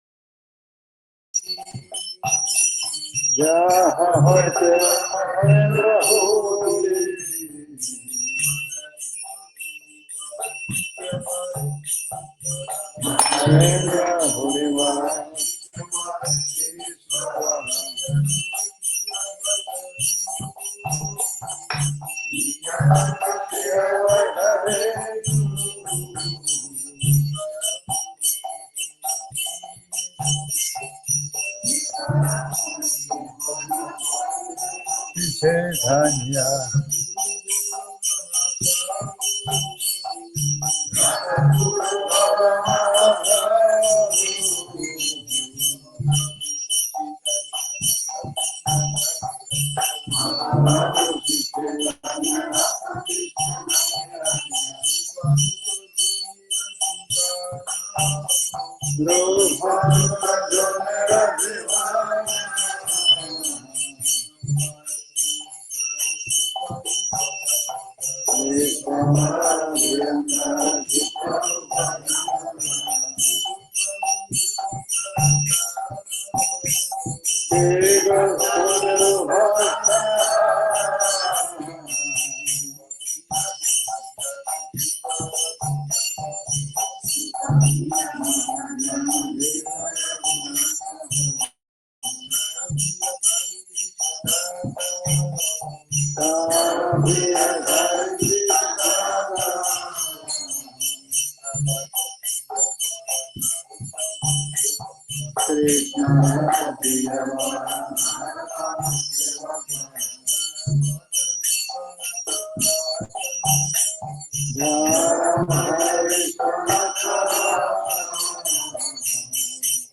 Кисельный (Москва)
Лекции полностью
Бхаджан
Киртан